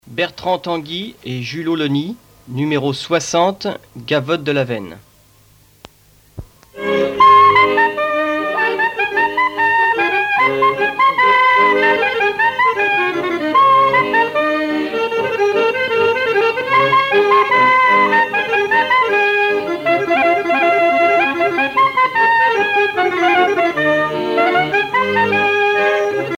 Mémoires et Patrimoines vivants - RaddO est une base de données d'archives iconographiques et sonores.
danse : gavotte bretonne
Pièce musicale éditée